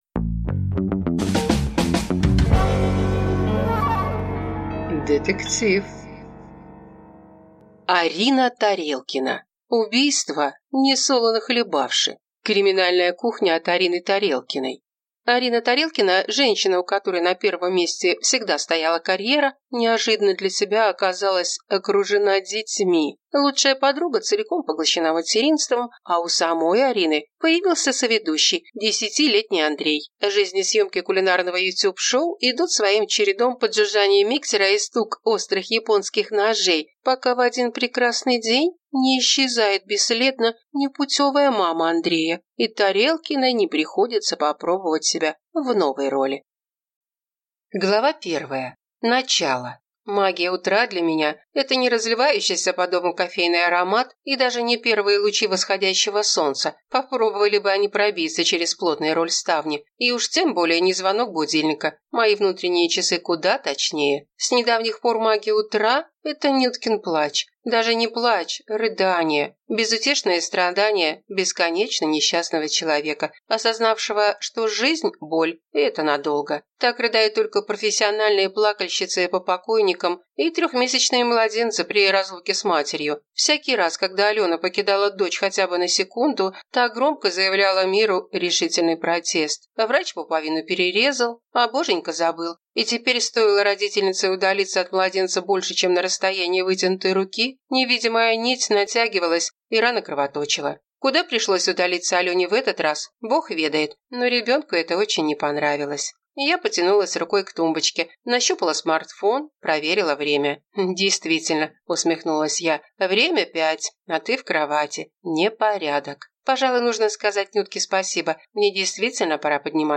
Аудиокнига Убийство несолоно хлебавши | Библиотека аудиокниг